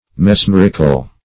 Search Result for " mesmerical" : The Collaborative International Dictionary of English v.0.48: Mesmeric \Mes*mer"ic\, Mesmerical \Mes*mer"ic*al\, a. [Cf. F. mesm['e]rique.]
mesmerical.mp3